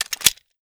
glock20_magin.wav